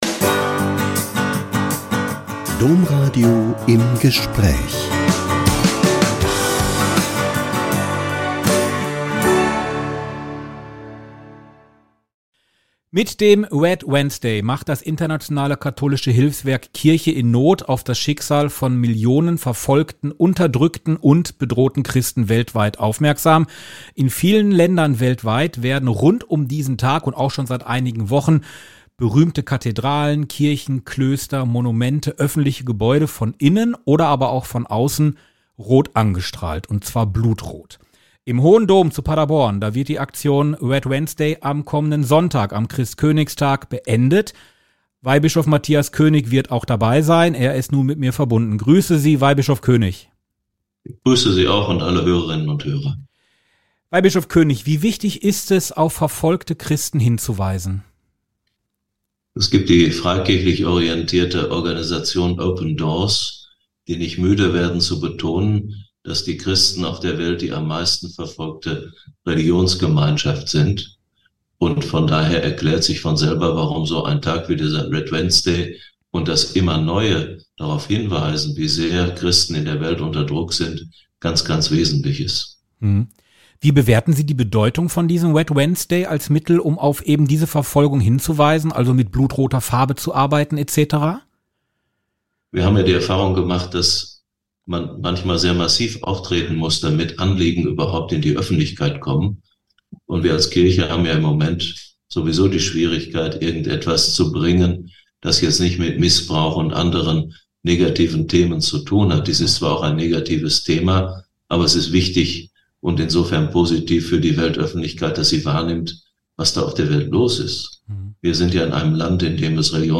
Red Wednesday mahnt Christenverfolgung an - Ein Interview mit Weihbischof Mathias König ~ Im Gespräch Podcast